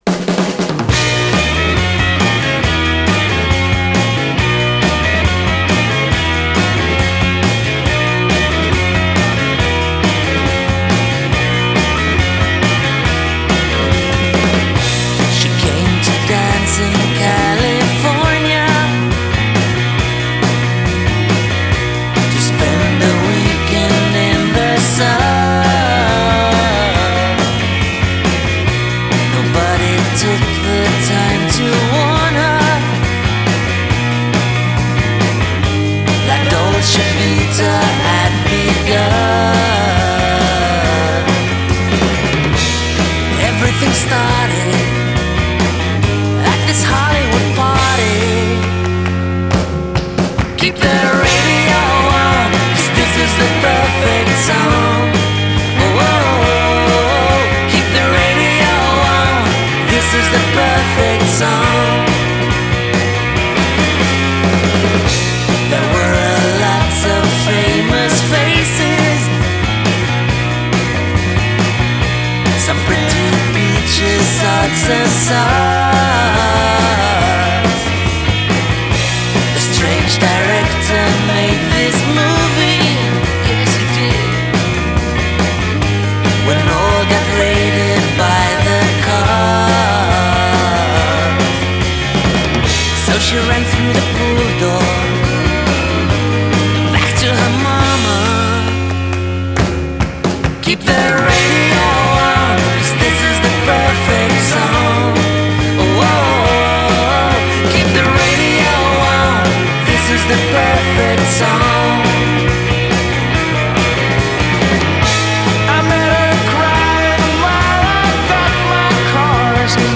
with that slightly sped up Beatles sound